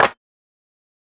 Claps
BattleCatClap3.wav